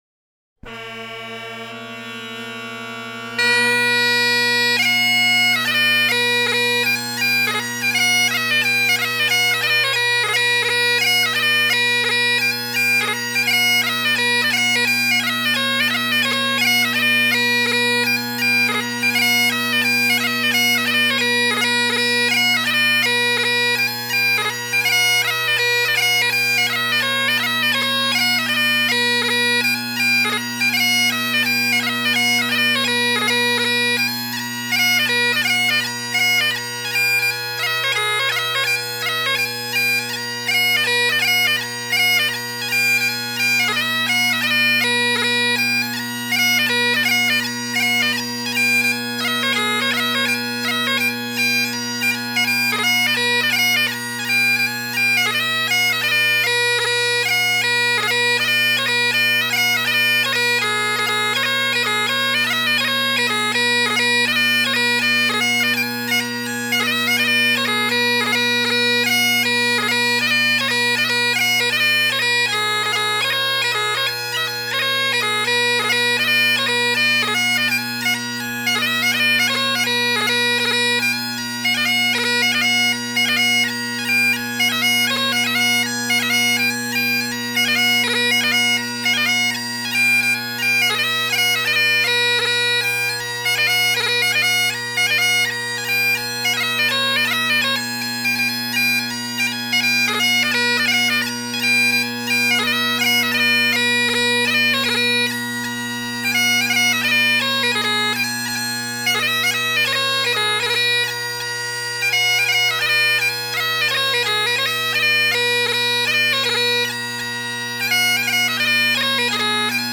in whose honor the famous 2/4 march was written.
Second tune in set
First tune By Argyll and Sutherland pipe Band